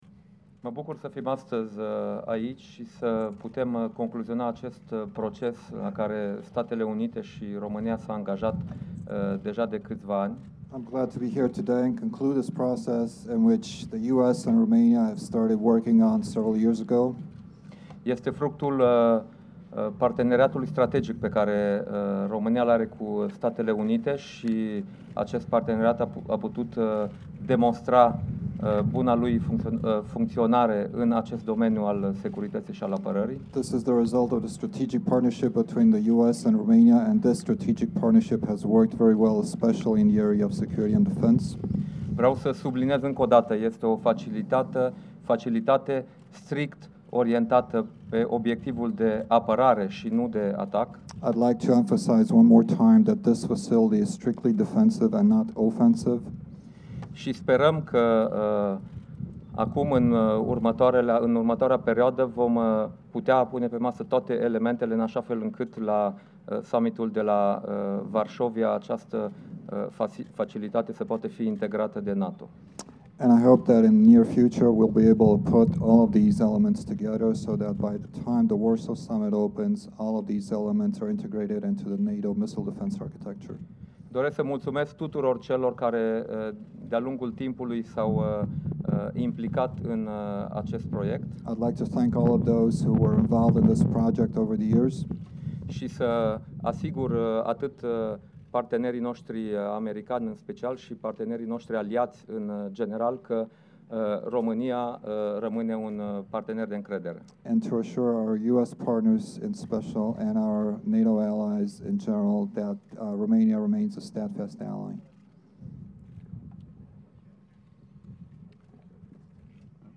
Joint press point by NATO Secretary General Jens Stoltenberg with Romanian Prime Minister Dacian Cioloș and US Deputy Secretary of Defense Robert Work following the Aegis Ashore operationalisation ceremony at Deveselu base, Romania